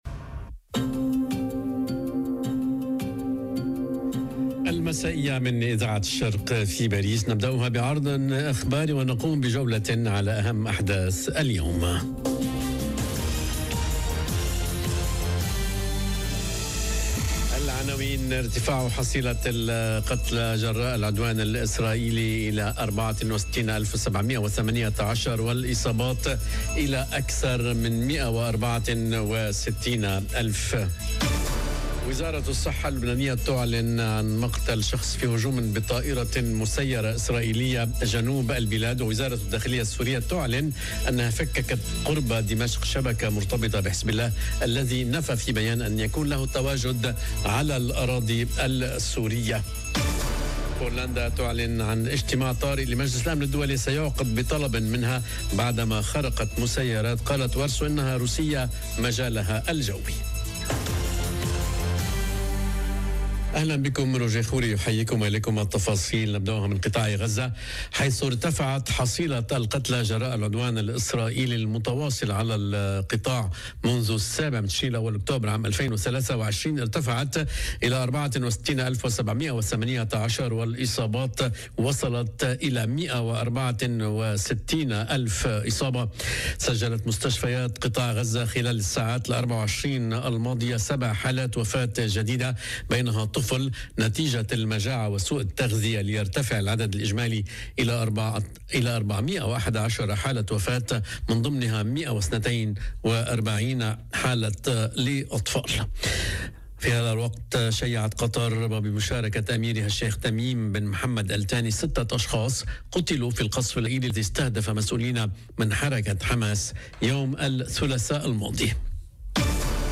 نشرة أخبارالمساء: استمرار ارتفاع حصيلة العدوان الإسرائيلي على غزة إلى إلى 64,718 - Radio ORIENT، إذاعة الشرق من باريس